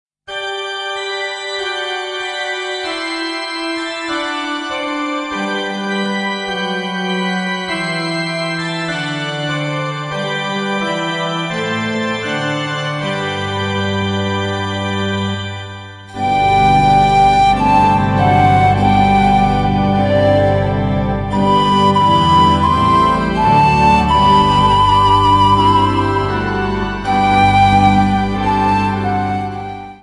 A compilation of Christmas Carols from Poland
panpipes